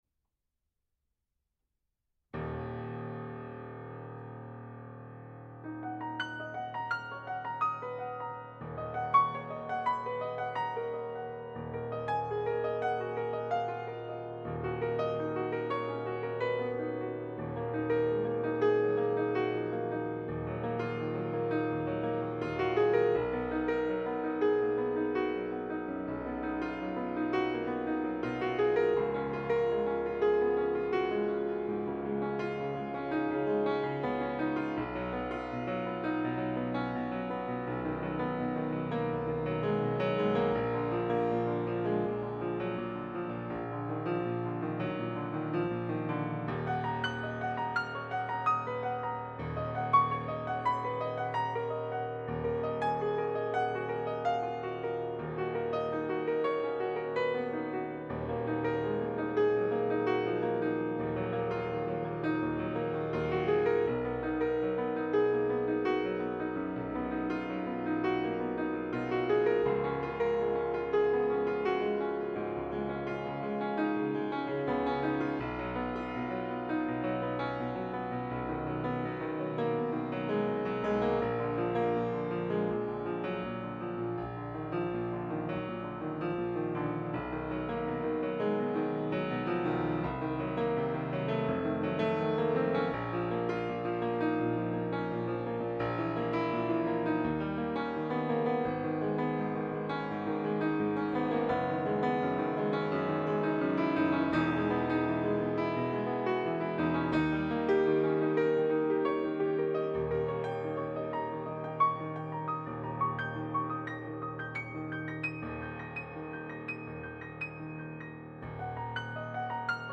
piano - romantique - melancolique - nostalgique - melodique